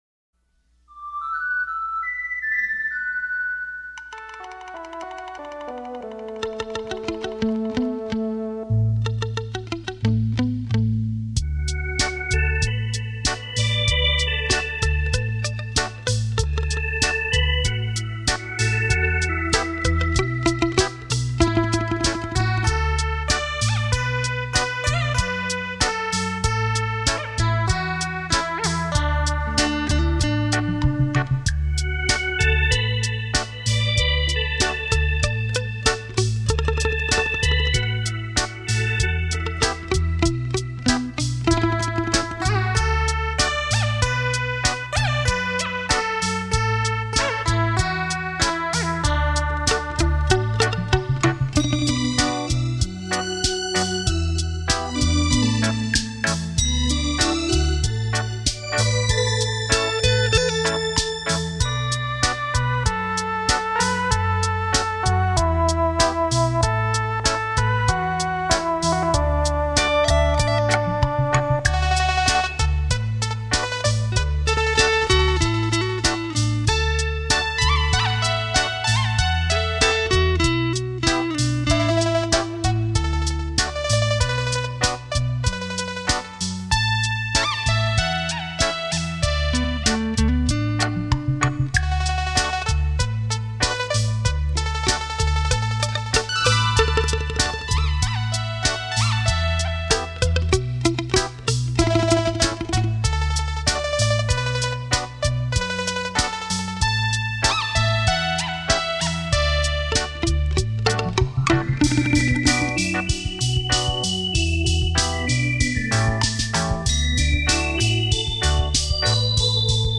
原音重现·超立体现场演奏